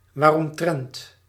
Ääntäminen
Ääntäminen US : IPA : [wɛɚ.ə.baʊts]